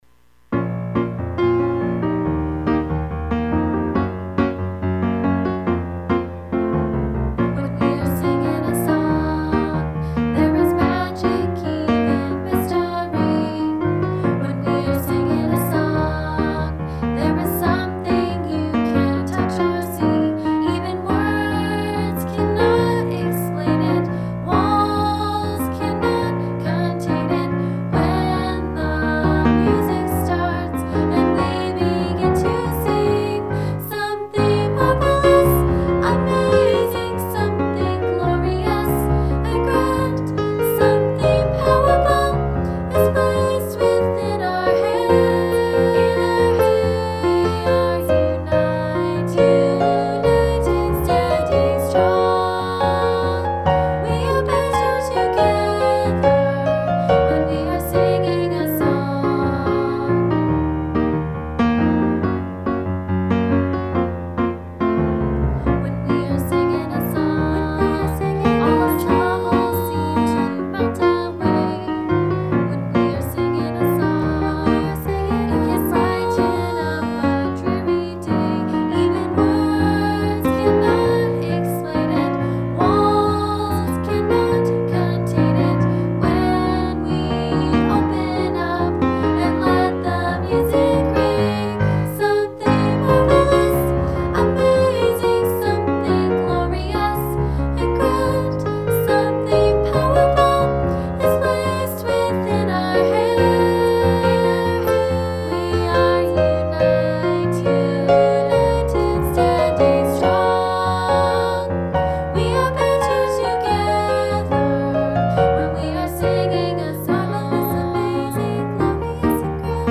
When We Are Singing a Song - Track with VOCALS